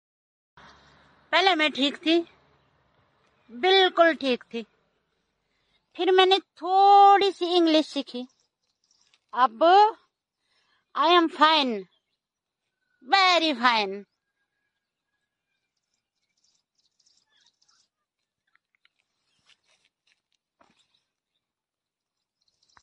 Haha 😆 sound effects free download